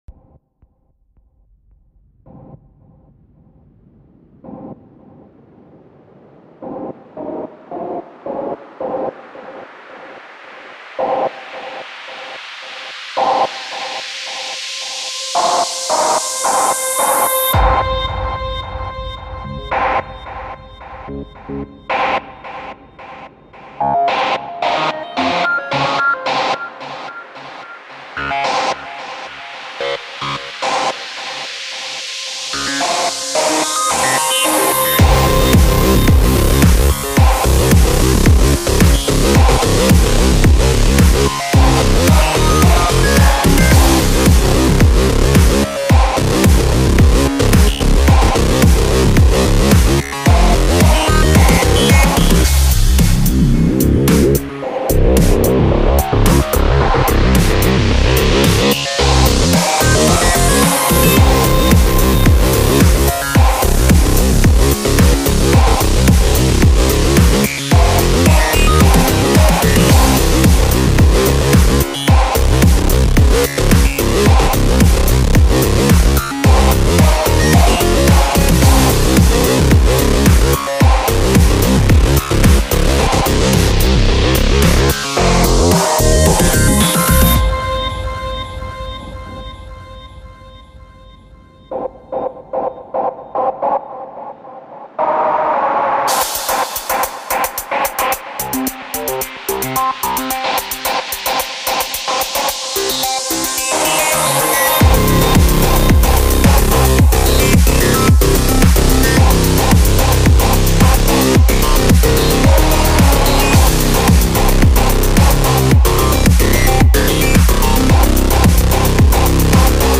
Industrial Music : SCARED mp3 format.